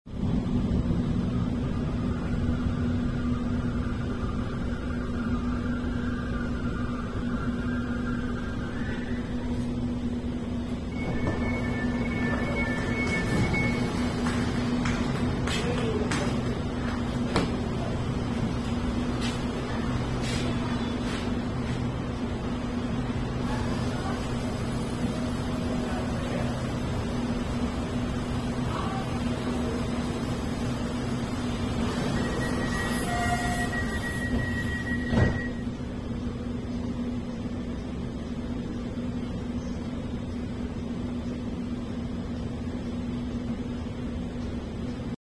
LRT 1 4th Generation Train Door sound effects free download
LRT-1 4th Generation Train Door Closing Sound. Similar Door Closing Sound For British Rail Class 345.